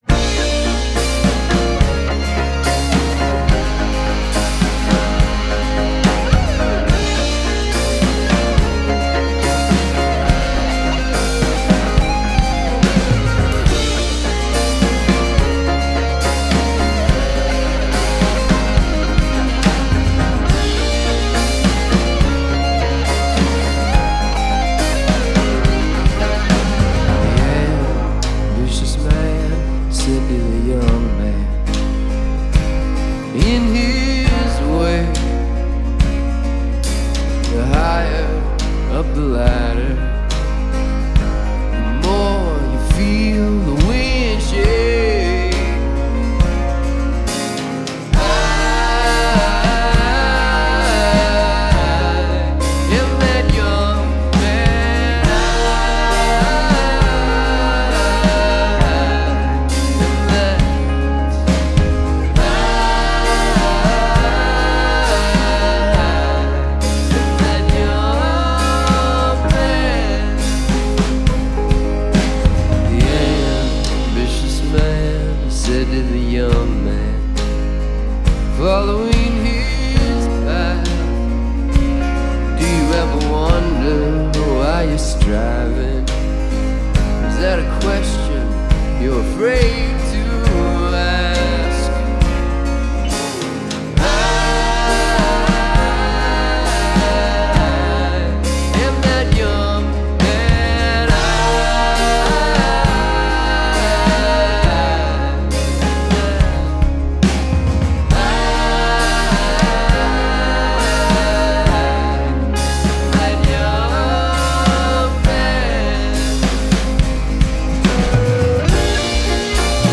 We believe in presenting artists exactly as they perform.